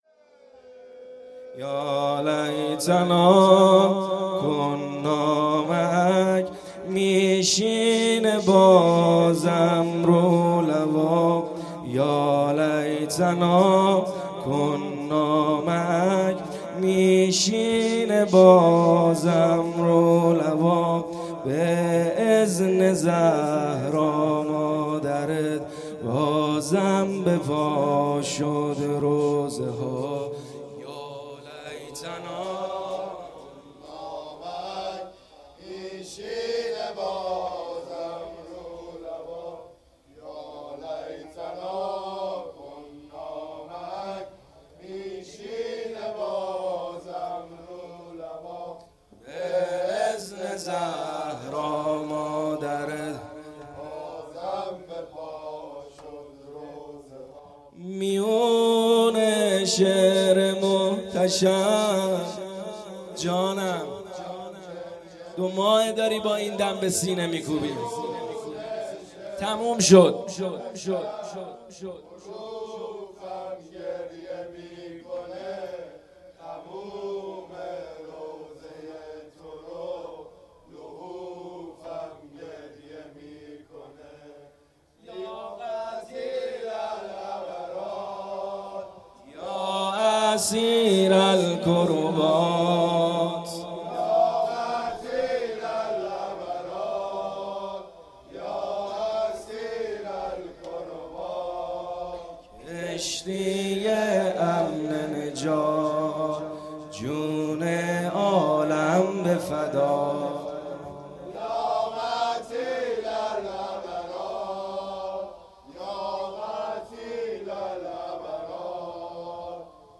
پیش زمینه - یالیتنا کنا معک